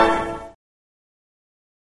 computer-error-soundbible_hKW9hdK.mp3